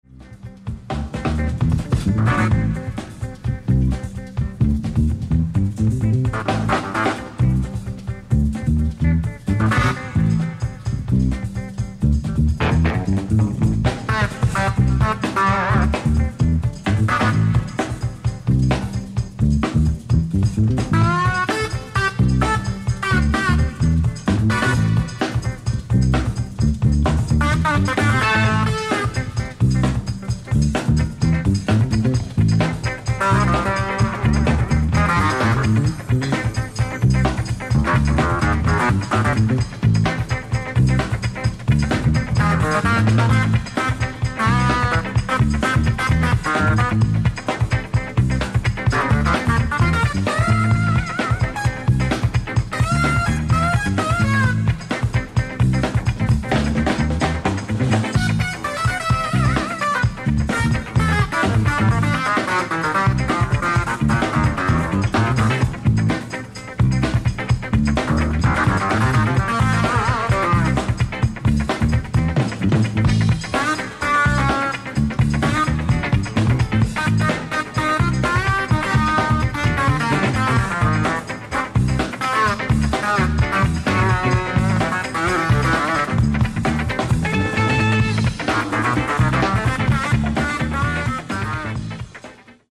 ライブ・アット・モントルー・ジャズフェスティバル、スイス
※試聴用に実際より音質を落としています。